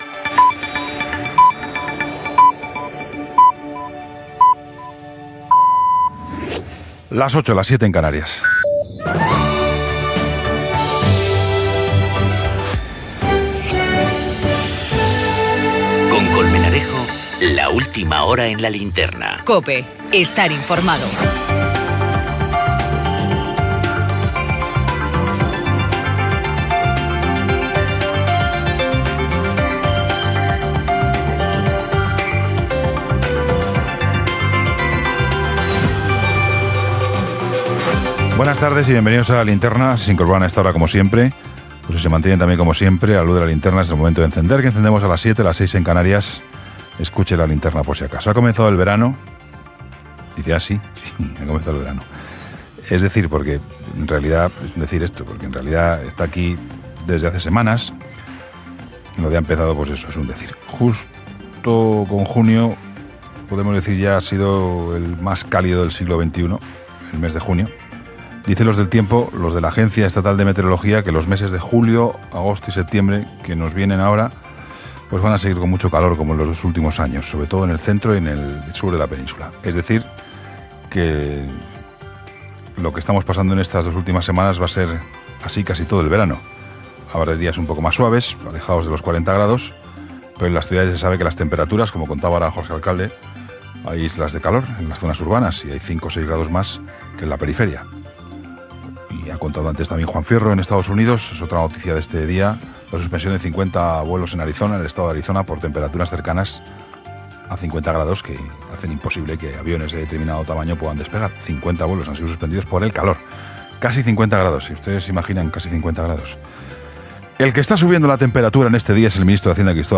Gènere radiofònic Informatiu
Presentador/a Colmenarejo, Juan Pablo